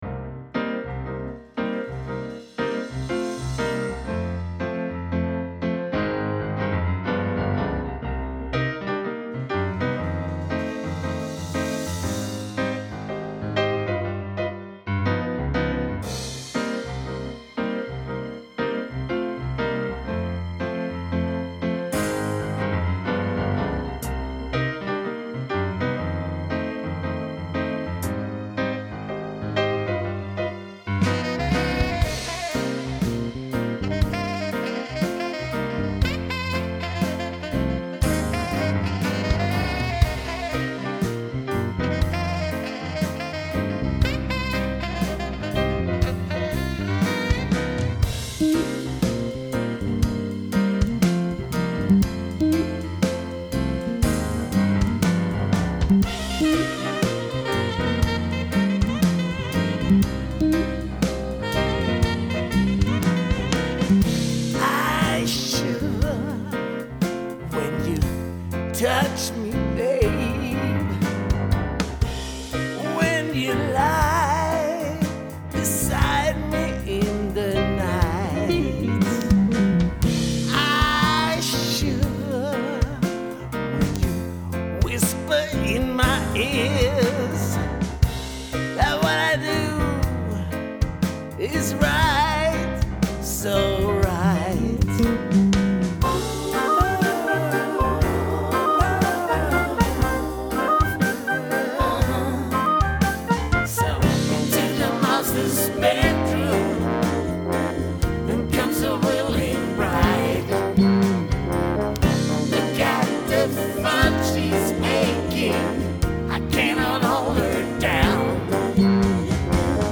Pop/Fusion